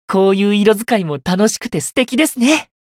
觉醒语音 こういう色使いも楽しくて素敵ですね！